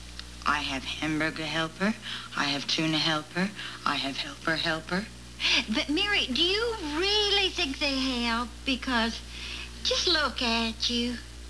Helper - Mary and Martha discuss how commercial products "help."  (247kb)